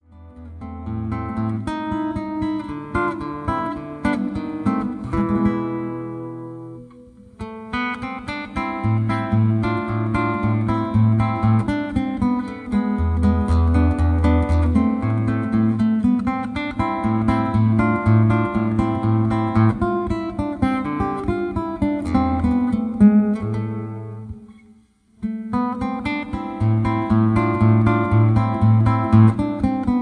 Soothing and Relaxing Guitar Music